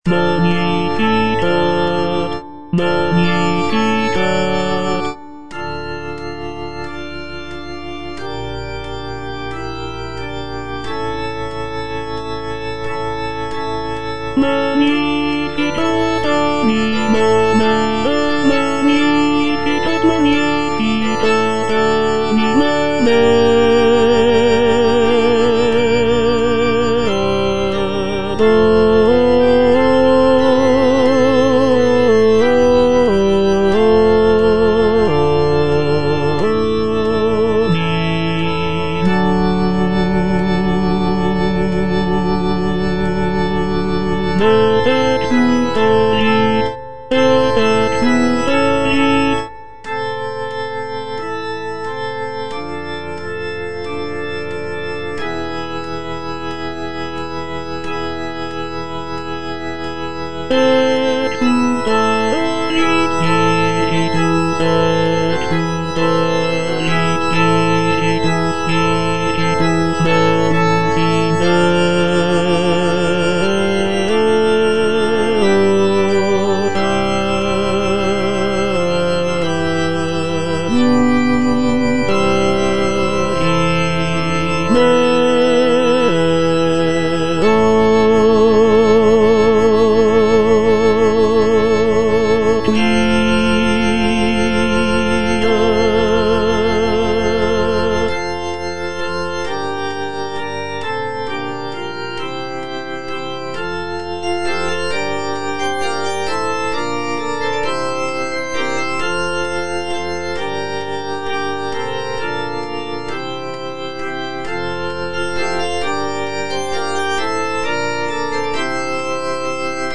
C. MONTEVERDI - MAGNIFICAT PRIMO (EDITION 2) Tenor I (Voice with metronome) Ads stop: auto-stop